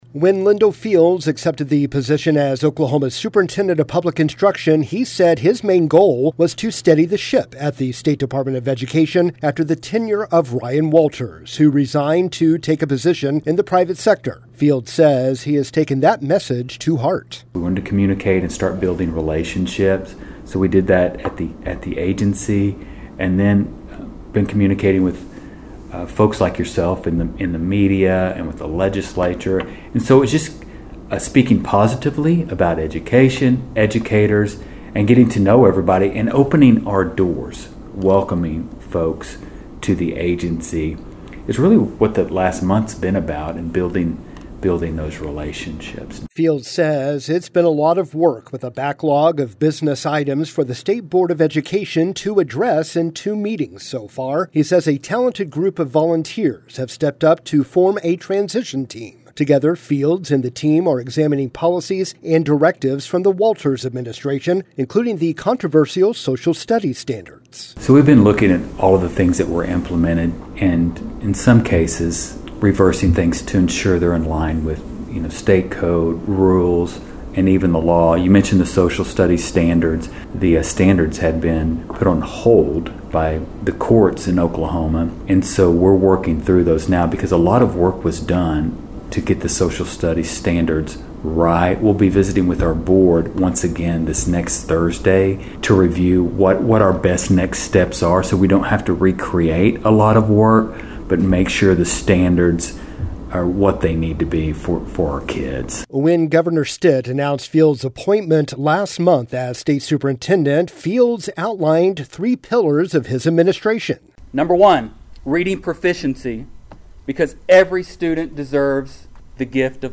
In a sit-down interview with Bartlesville Radio, Fields reflected on his first weeks in office and shared his vision for the future of Oklahoma education.